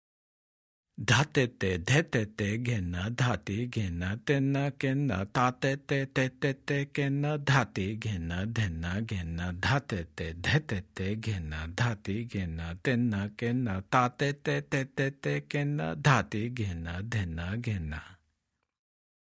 Spoken